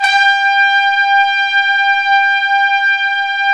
Index of /90_sSampleCDs/Roland LCDP06 Brass Sections/BRS_Tpts mp)f/BRS_Tps Velo-Sw